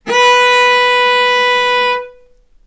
vc-B4-ff.AIF